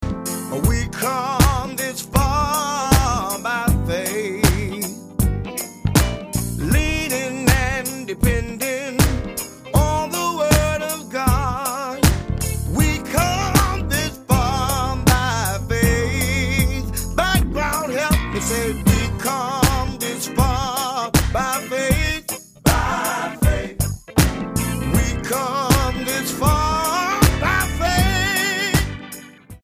STYLE: Gospel
Sharp production gives it a strong sound